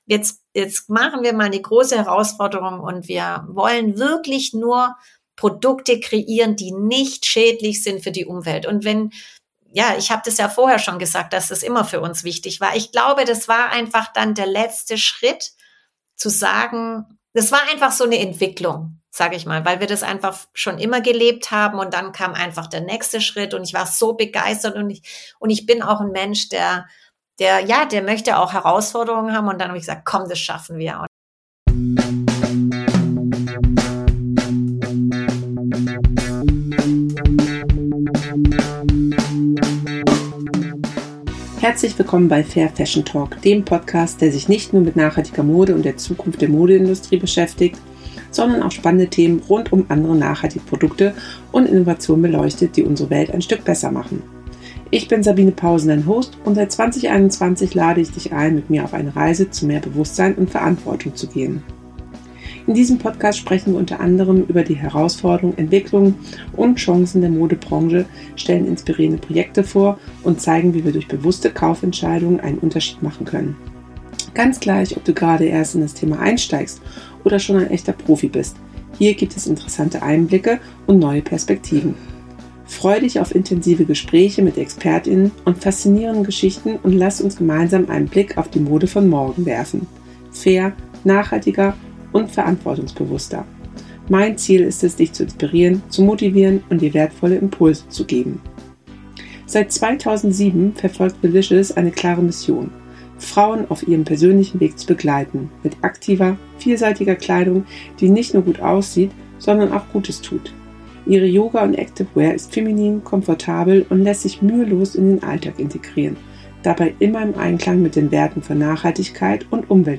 In dem Podcast „Fair Fashion Talk“ möchte ich alle Themen und Fragen rund um nachhaltige Mode beantworten und diskutieren. In Gesprächen mit Experten, Organisationen und Fair Fashion Labels werde ich mich austauschen, um Dir detailliertes Hintergrundwissen geben zu können, um Erfahrungen mit Dir zu teilen und neue innovative Projekte vorzustellen.